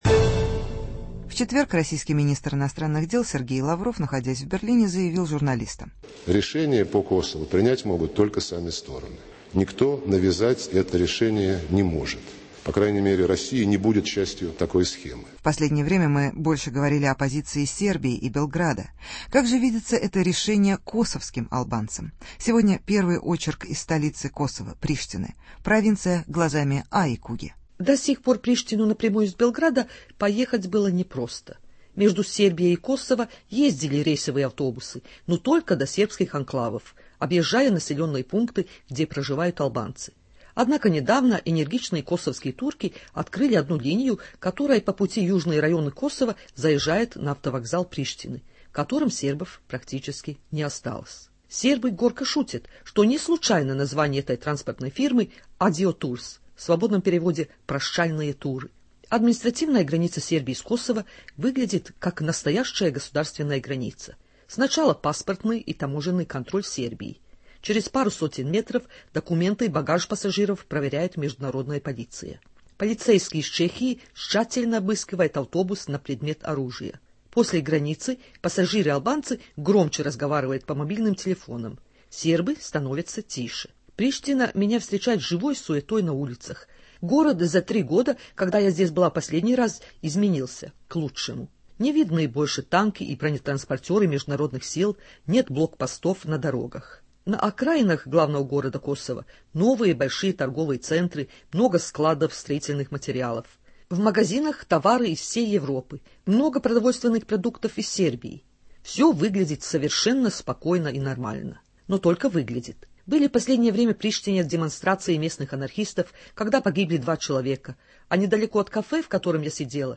Статус Косова: репортаж из Приштины